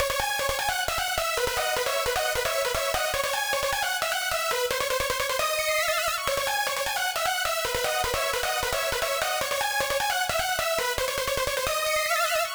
1トラック目のシンセブラス系のメインリフと、
2トラック目のキラキラしたシンセリードによるカウンターメロディです。